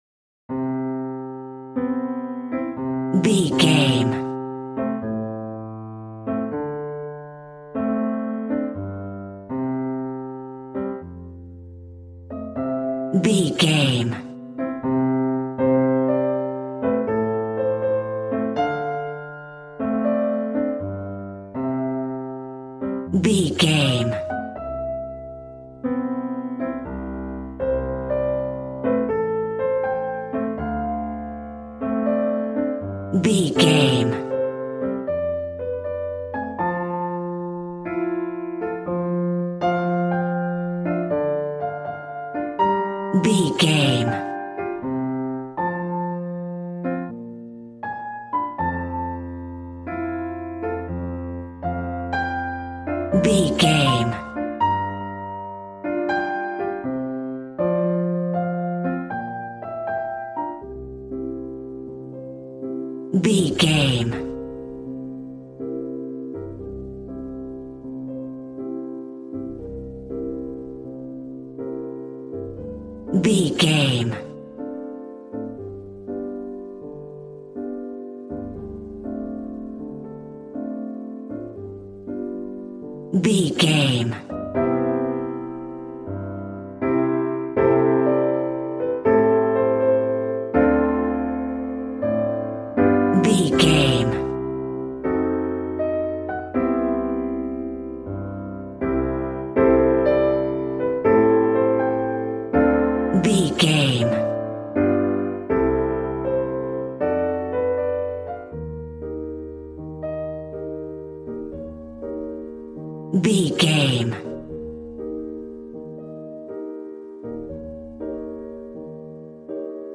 Smooth jazz piano mixed with jazz bass and cool jazz drums.,
Ionian/Major
smooth
piano
drums